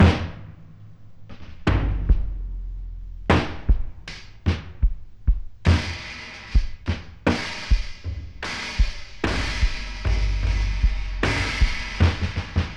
Groovy Fill.wav